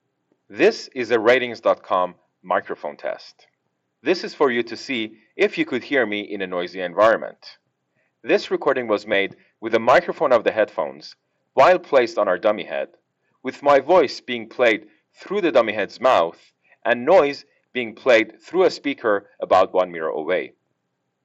our recording to hear what speech sounds like through these cans.